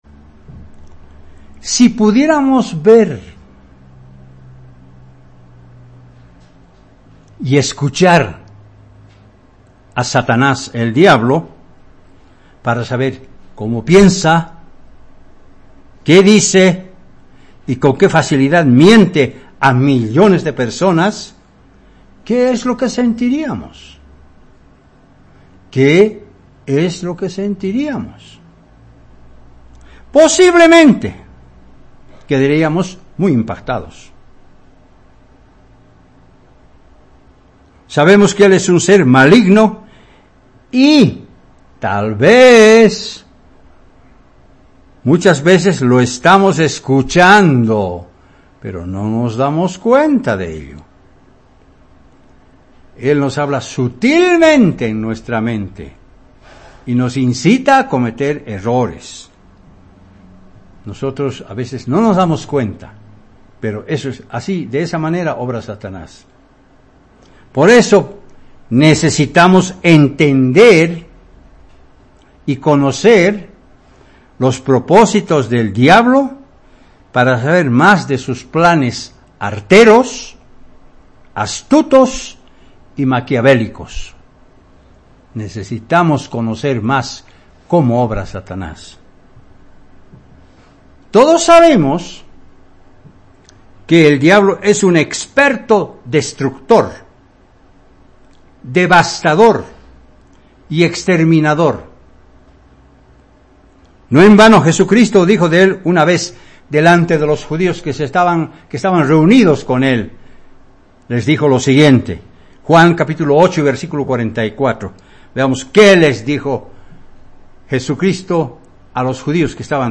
Es importante conocer los propósitos de Satanás para poder defendernos de sus ataques. Mensaje entregado el 5 de mayo de 2018.